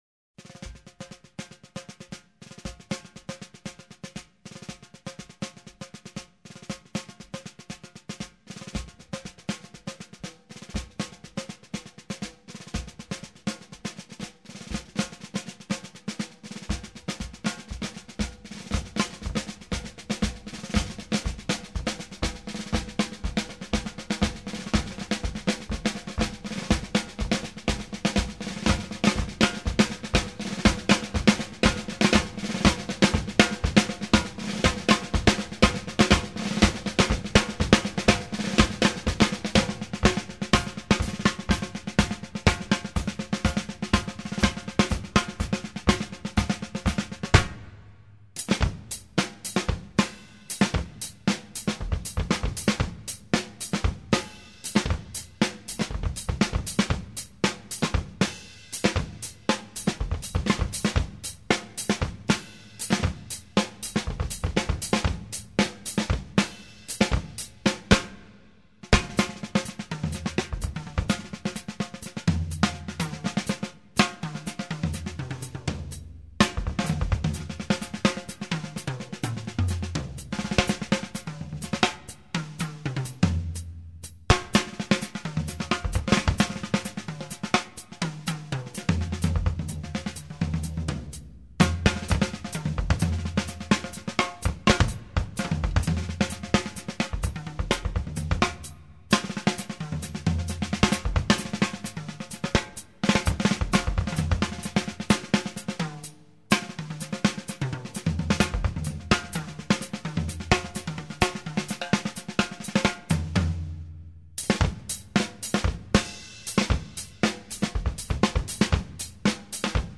劲度震撼 发烧录音